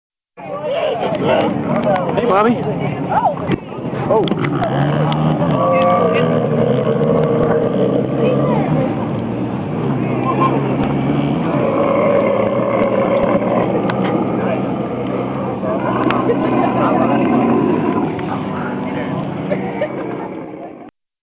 Camels in Dubai